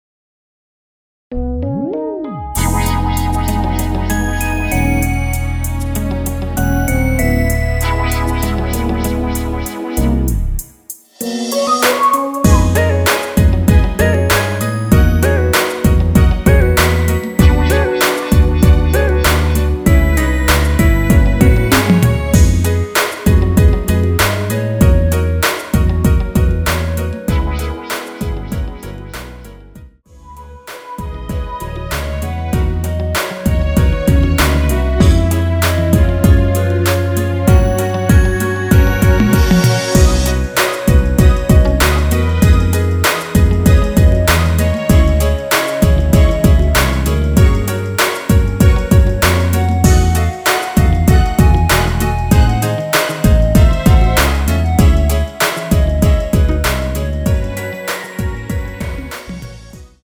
원키에서(-1)내린 멜로디 포함된 MR 입니다.(미리듣기 참조)
앞부분30초, 뒷부분30초씩 편집해서 올려 드리고 있습니다.